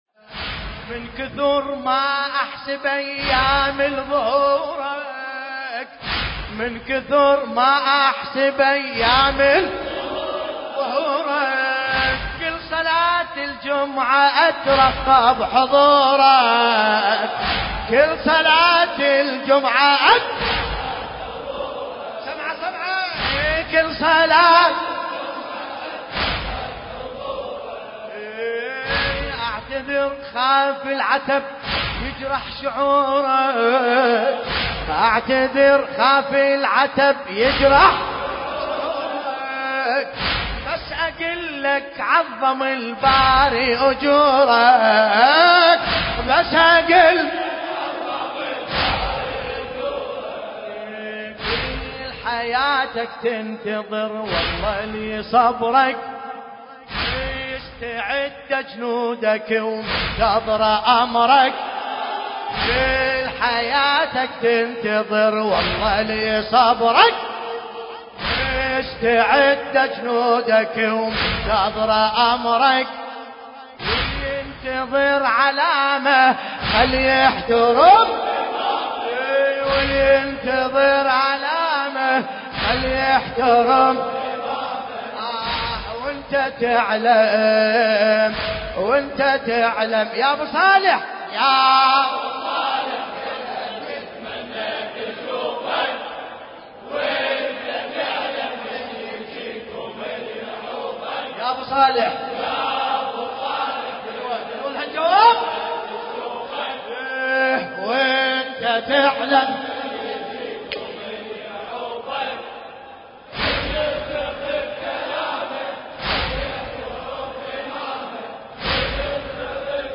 ساحة سيد الشهداء المركزية البصرة